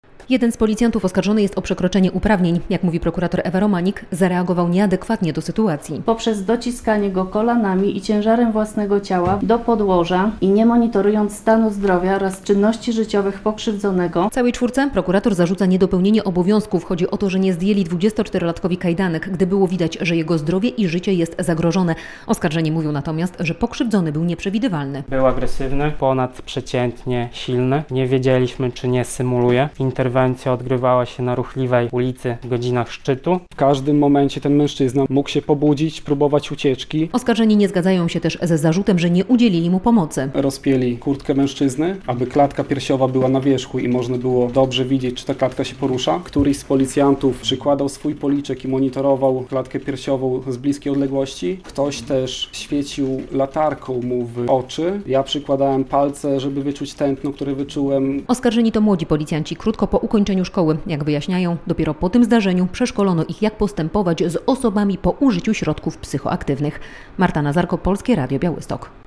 Proces dotyczący policyjnej interwencji - relacja